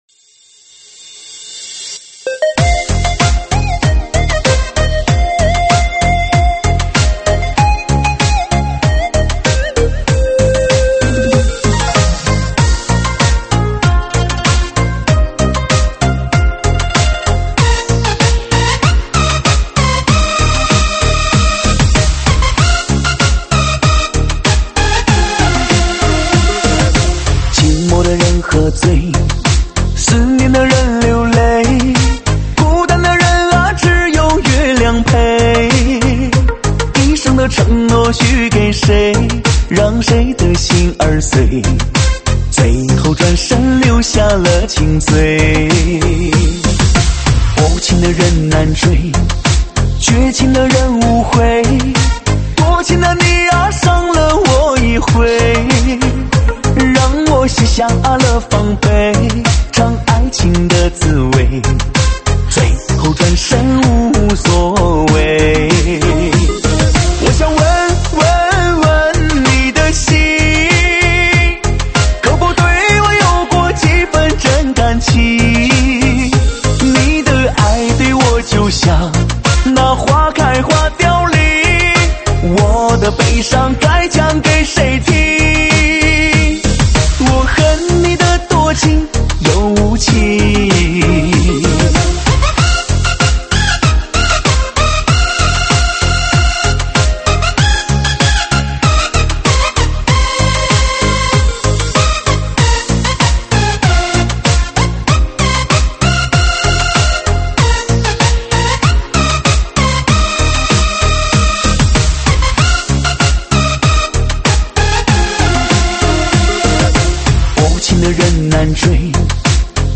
舞曲类别：中文慢摇